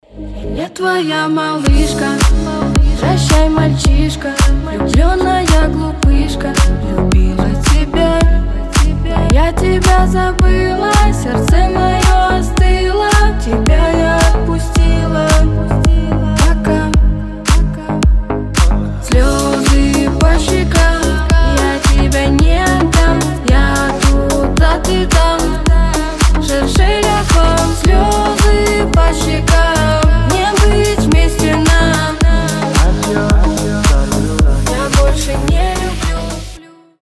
• Качество: 320, Stereo
лирика
грустные
красивый женский голос